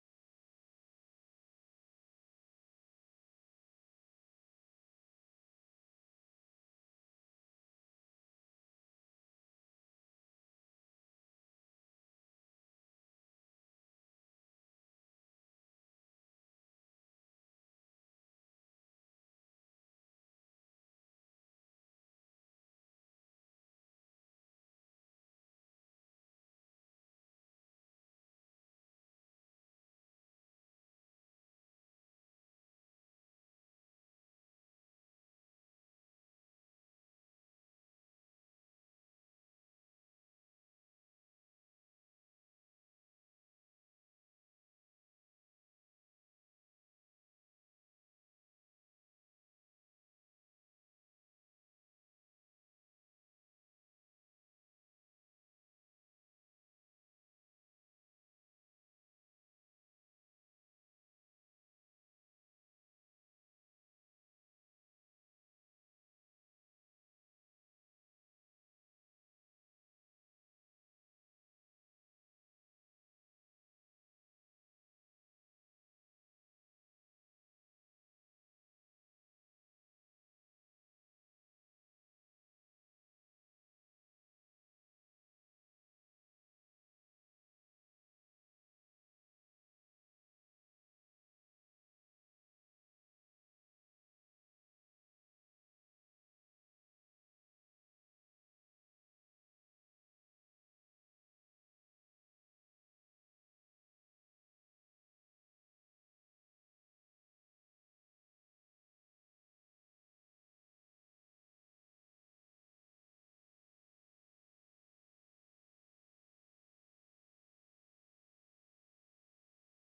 Sons of Liberty Radio Talk Show